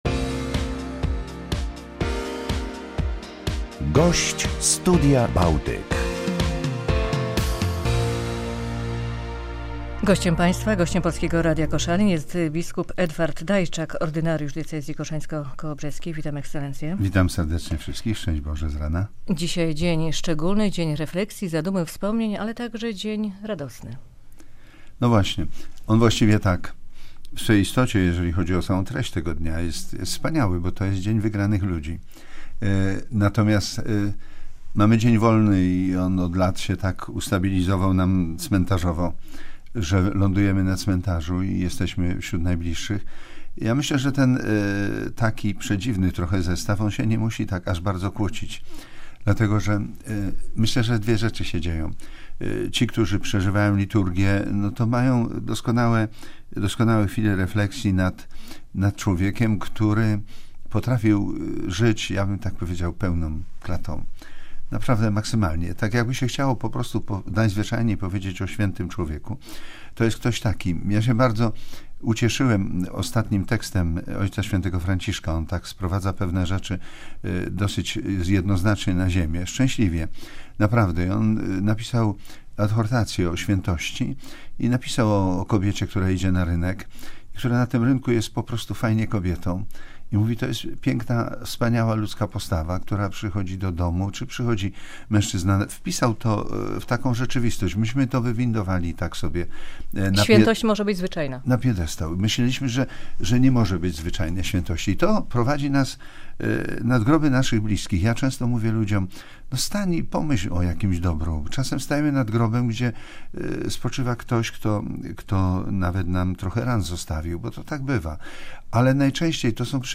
Ordynariusz diecezji koszalińsko-kołobrzeskiej było gościem porannego "Studia Bałtyk" w Polskim Radiu Koszalin.